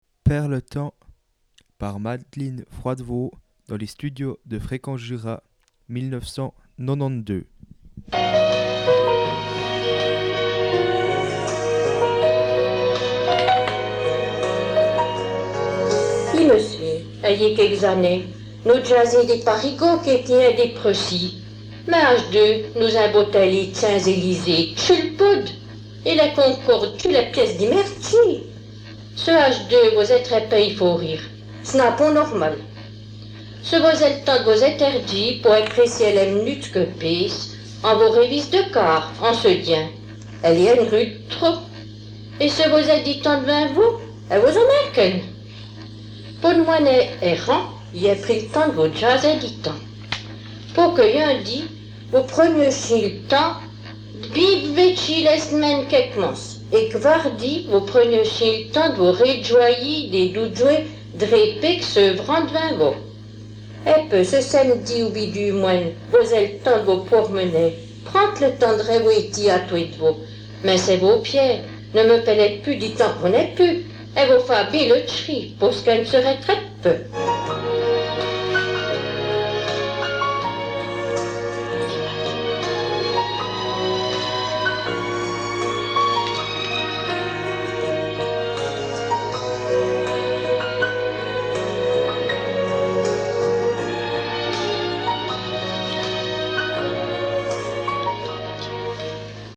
Récit
enregistré dans les studios de Fréquence Jura.